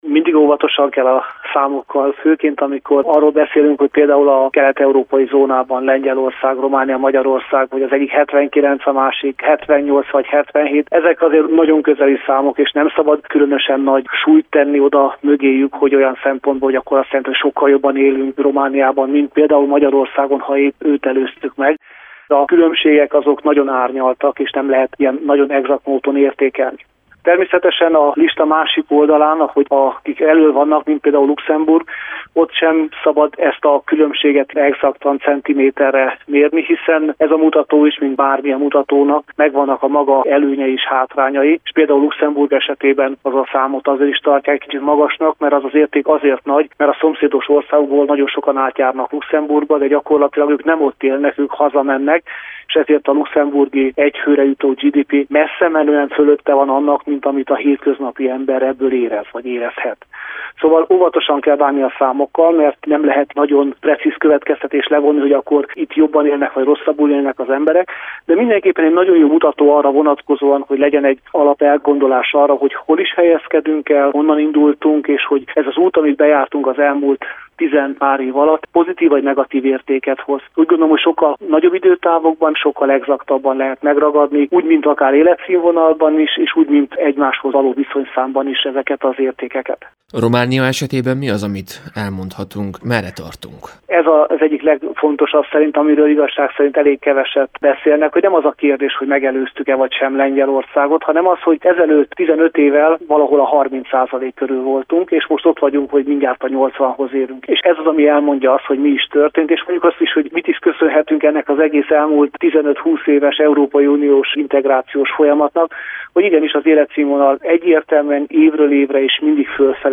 Közgazdásszal néztünk az Eurostat előzetes adatai mögé.